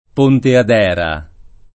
Pontedera [ponted$ra; non -t%-] (ant. Pontadera [pontad$ra] o Ponte ad Era [